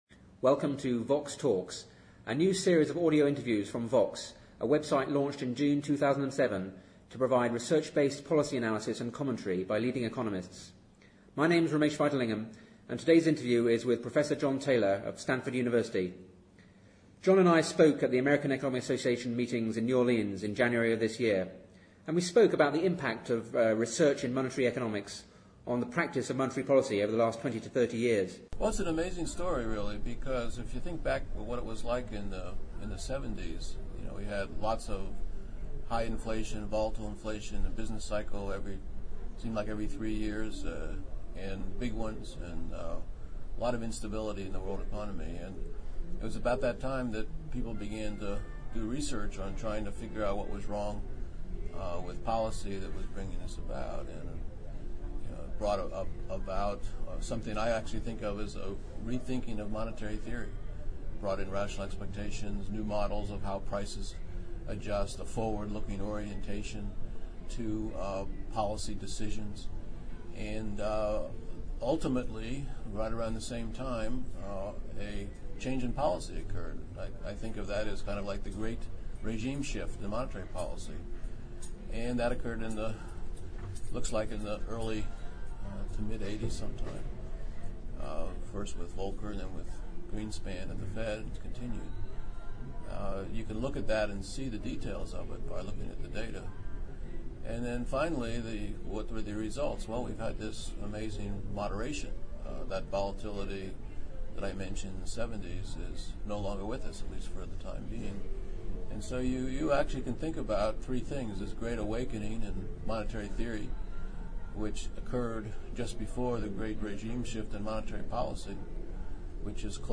The interview was recorded at the American Economic Association meetings in New Orleans in January 2008 and based on a paper in which he discusses the links between the ‘great awakening’ in monetary theory, the ‘great regime shift’ in monetary policy and the ‘great moderation’ in macroeconomic volatility over the past 30 years.